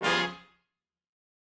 GS_HornStab-G7b2b5.wav